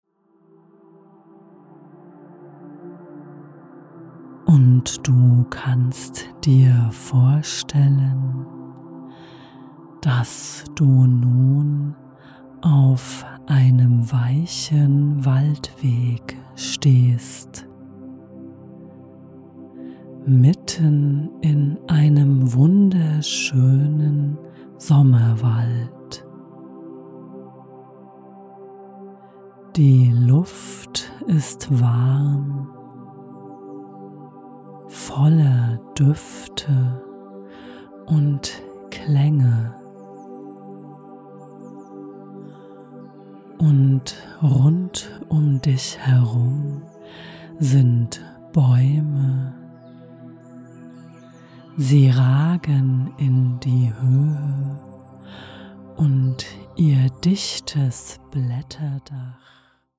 Fantasiereise Sommerwald - Via Fantasia - geführte Meditationen
Die einfühlsame Begleitung führt dich tiefer in diese Atmosphäre hinein, sodass du dich getragen fühlst von der Fülle und Harmonie des Sommers.